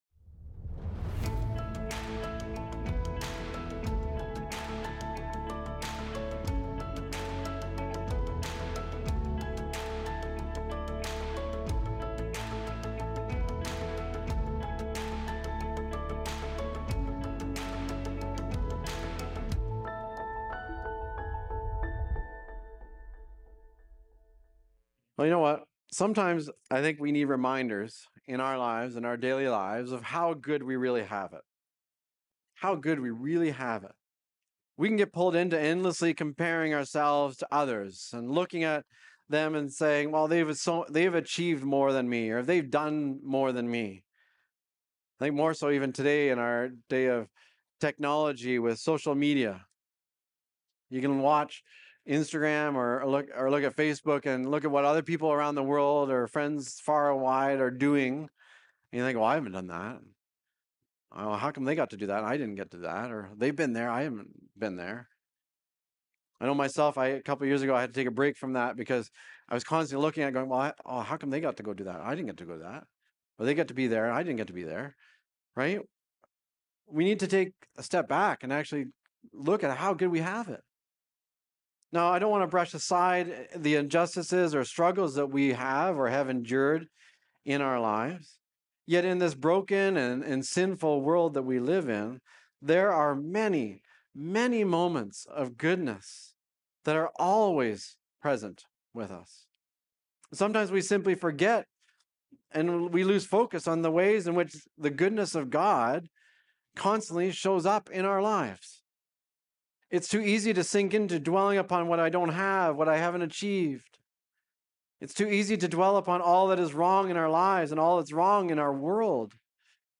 Recorded Sunday, August 17, 2025, at Trentside Fenelon Falls.